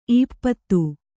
♪ īppattu